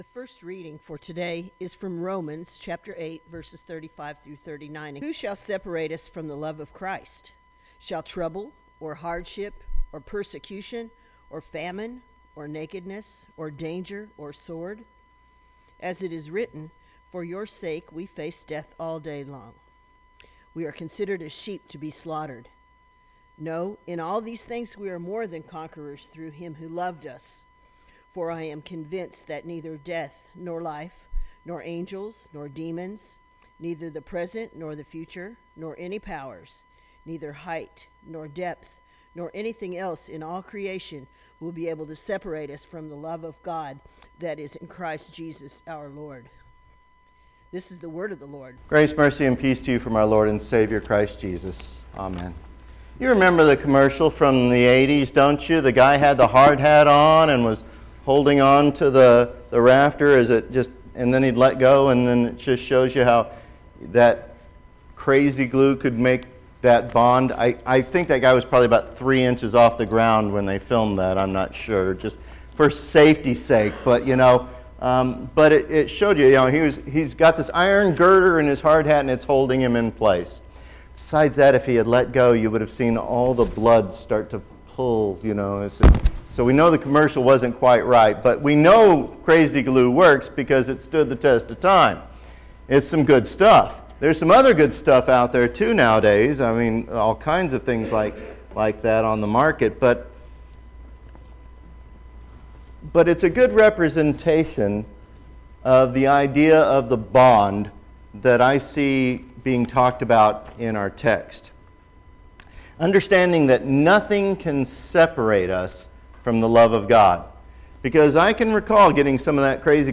Sermons - Holy Cross Lutheran Church